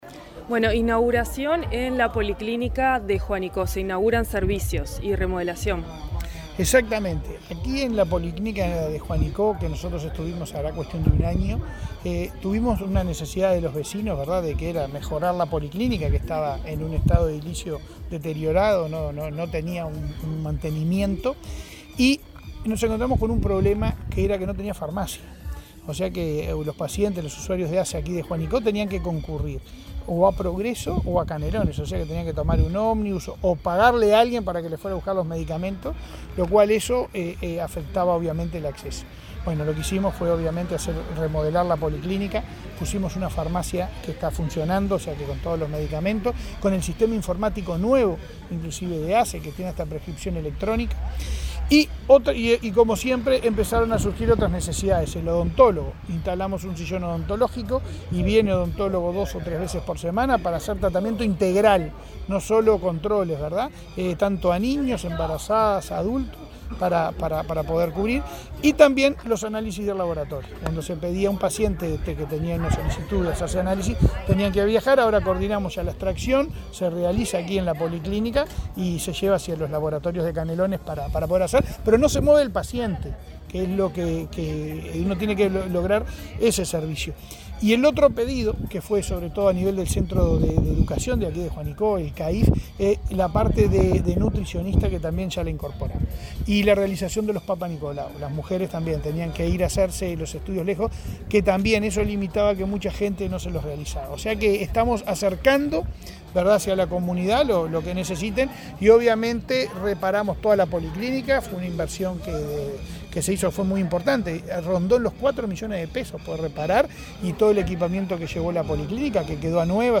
Entrevista al presidente de ASSE, Leonardo Cipriani
El presidente de ASSE, Leonardo Cipriani, dialogó con Comunicación Presidencial en Canelones, donde inauguró obras de remodelación en el servicio de